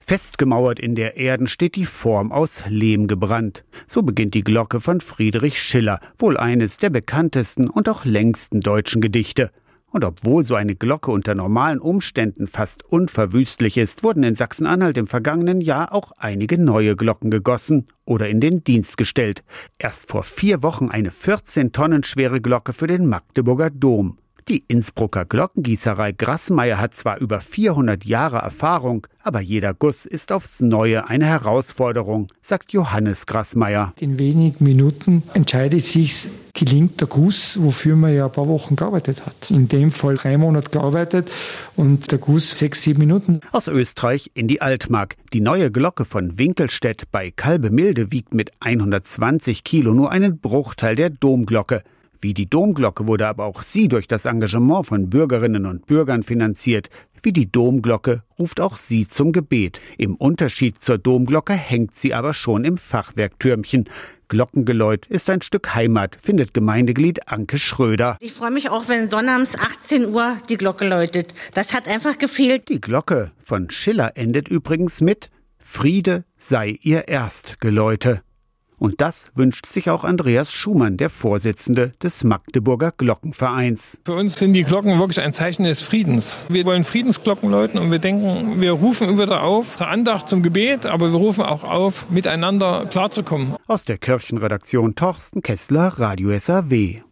Interviewte